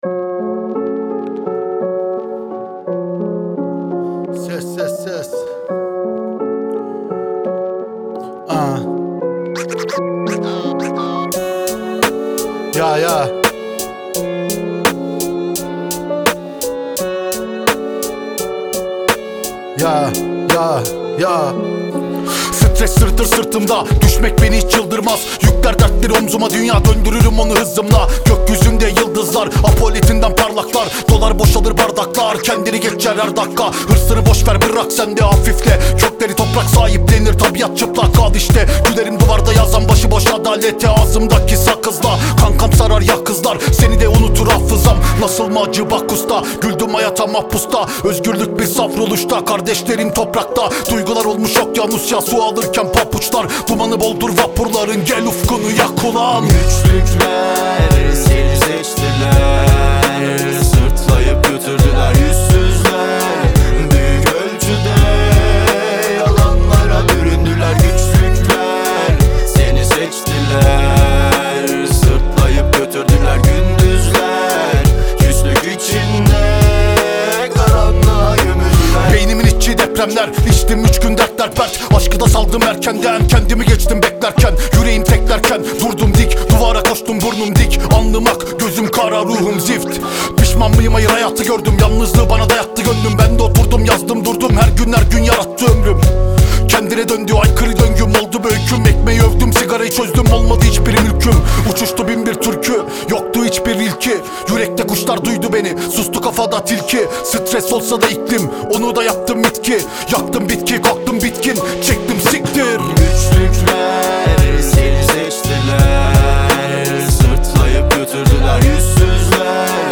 Трек размещён в разделе Турецкая музыка / Рэп и хип-хоп.